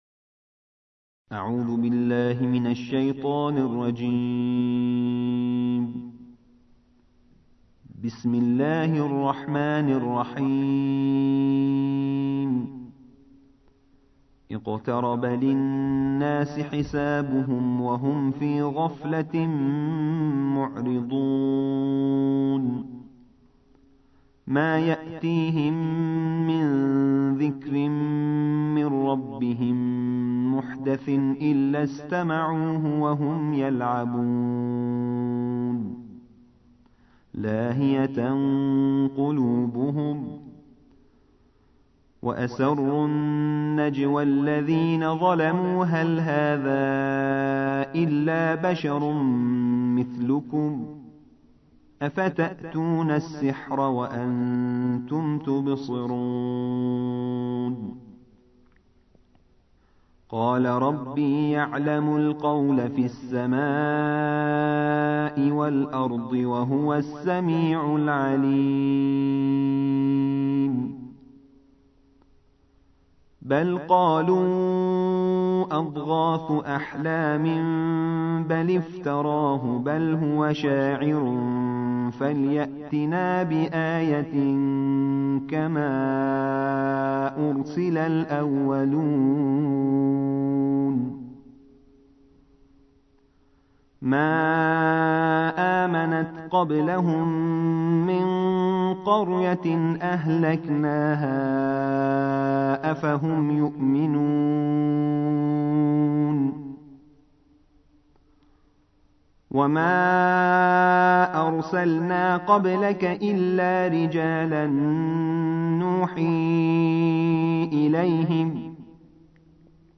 الجزء السابع عشر / القارئ